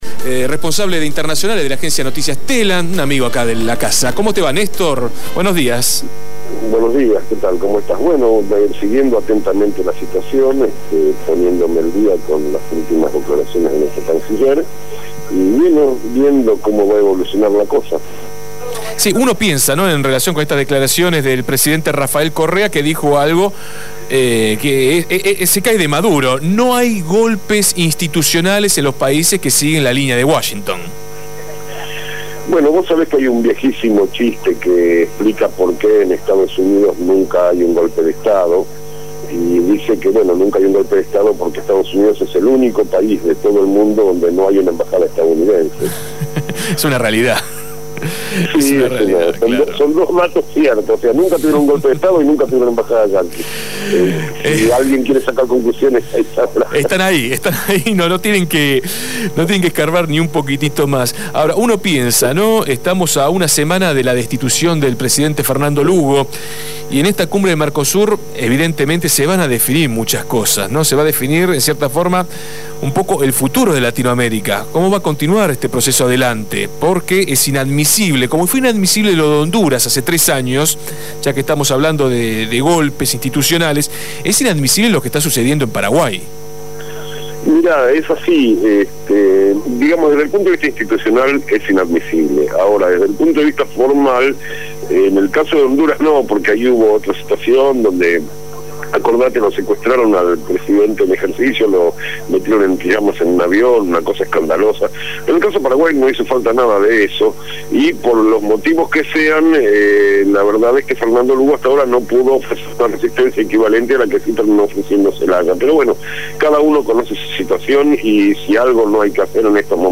analista internacional